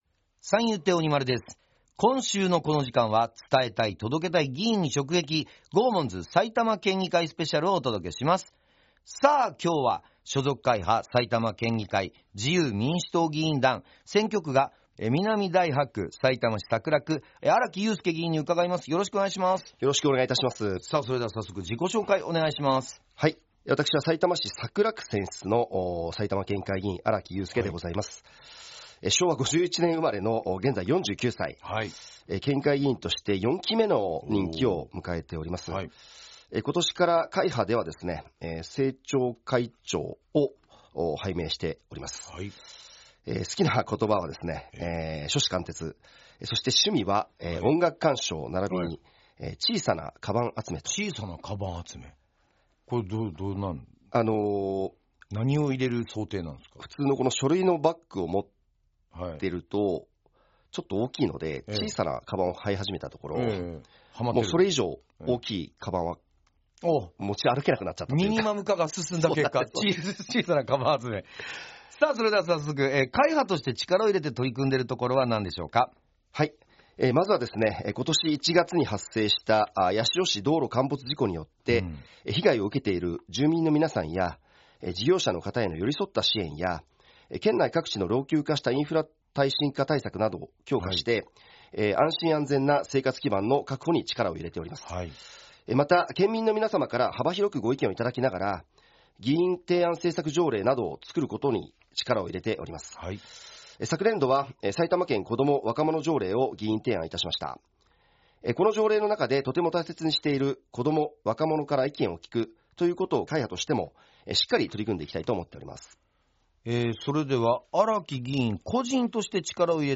県議会議長や主要会派の議員が「GOGOMONZ」パーソナリティーで落語家の三遊亭鬼丸さんと、所属会派の紹介、力を入れている分野、議員を志したきっかけ、地元の好きなところなどについて軽快なトークを展開しました。
11月10日（月曜日）と11月11日（火曜日）にFM NACK5のスタジオにてラジオ収録が行われました。